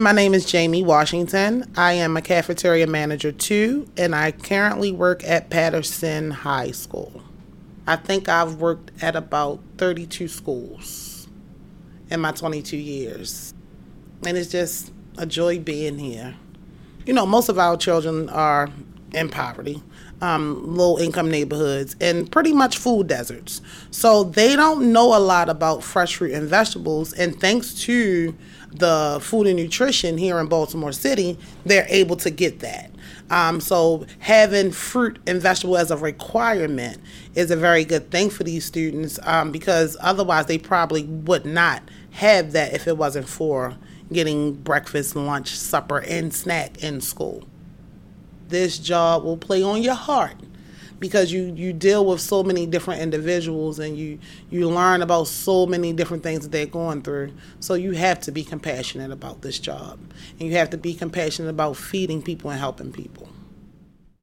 Job title: Cafeteria Manager II